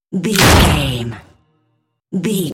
Dramatic hit wood
Sound Effects
heavy
intense
dark
aggressive
hits